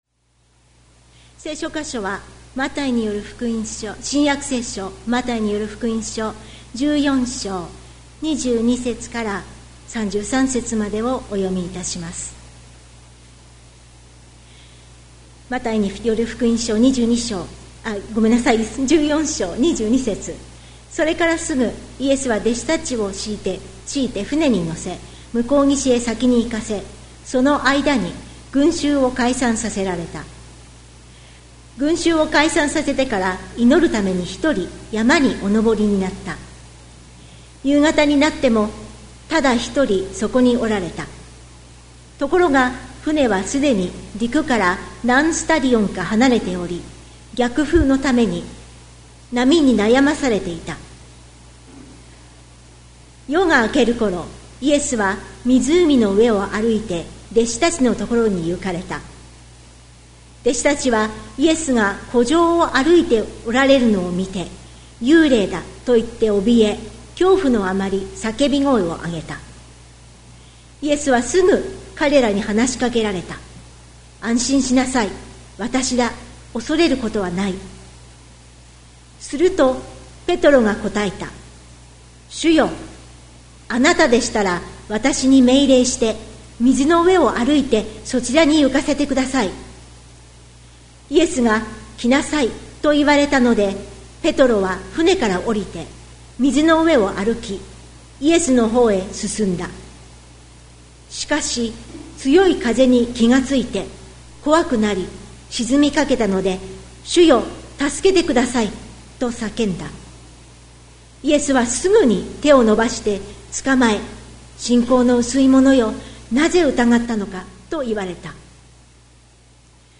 2026年02月08日朝の礼拝「安心しなさい」関キリスト教会
説教アーカイブ。